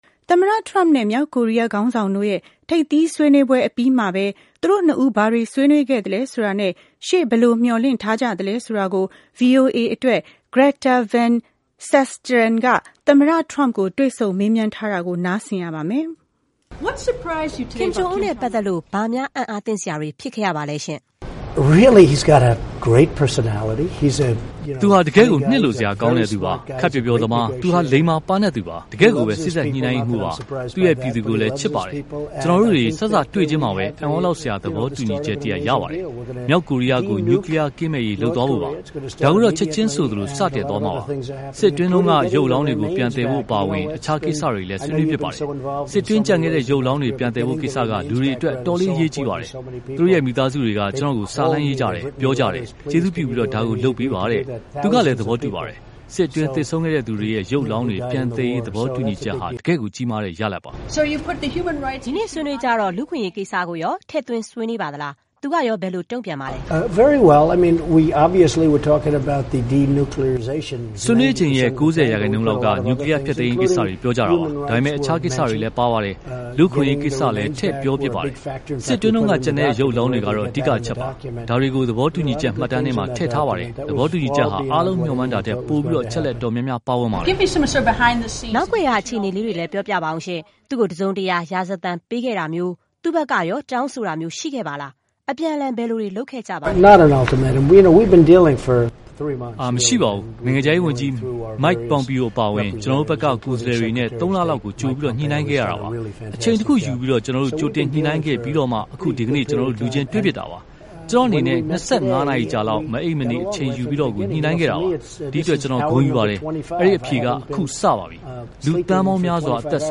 VOA နဲ့ သမ္မတ Trump တွေ့ဆုံမေးမြန်းမှု